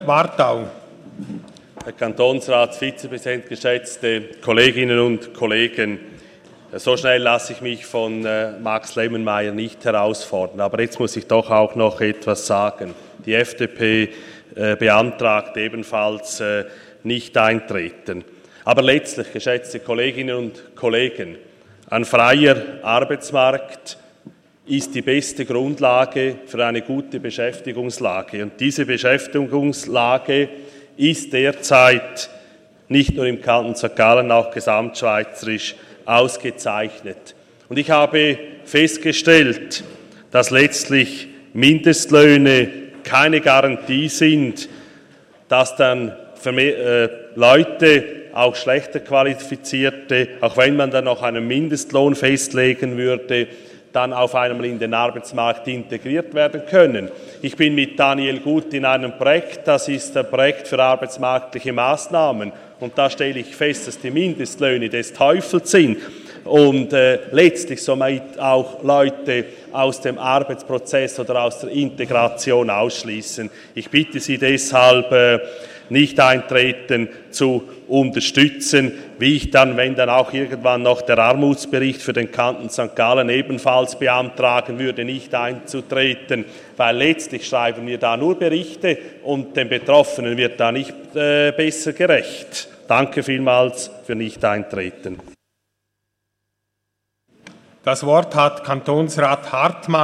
13.6.2018Wortmeldung
Session des Kantonsrates vom 11. bis 13. Juni 2018